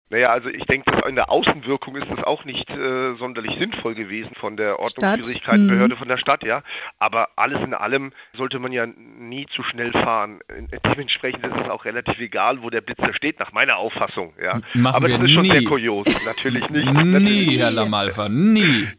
Verkehrsanwalt